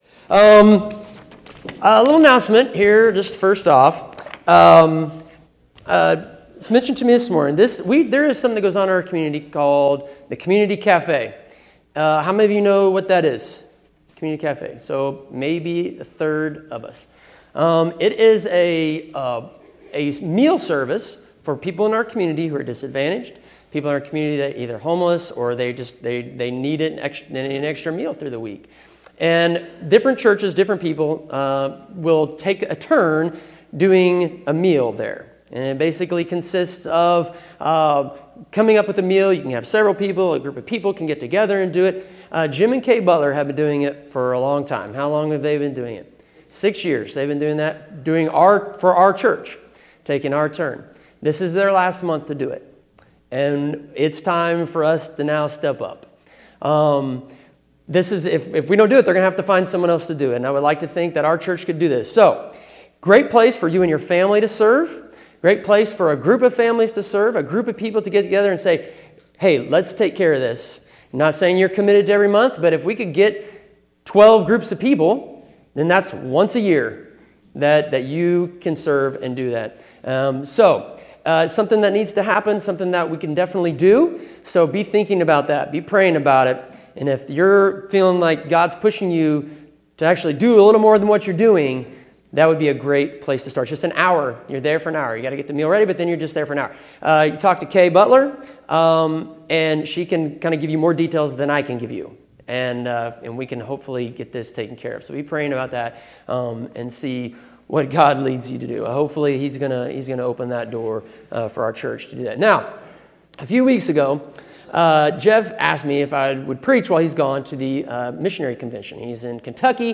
Sermon Summary Receiving salvation is the greatest gift anyone could receive, but for many the enormity of what has been done for us is lost in the familiarity of the Gospel. How can we know if we are truly grateful for God's grace poured out on us through faith?